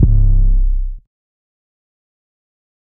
808 (80 Degrees).wav